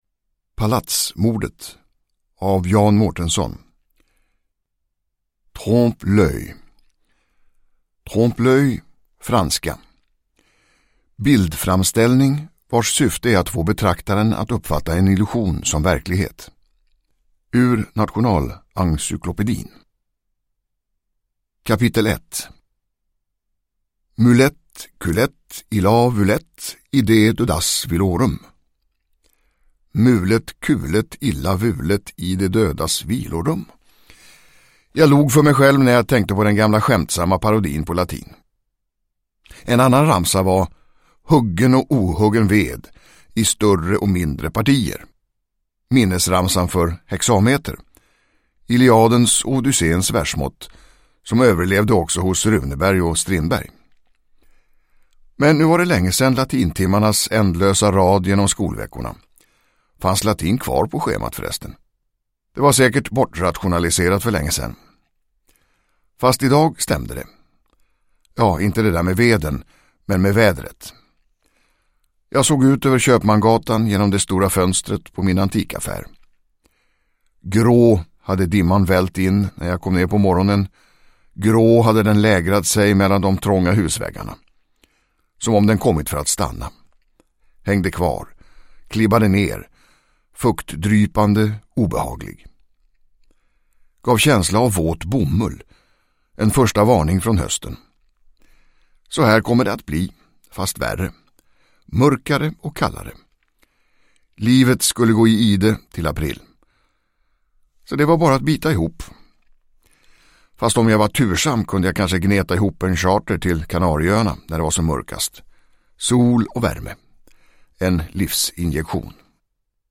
Downloadable Audiobook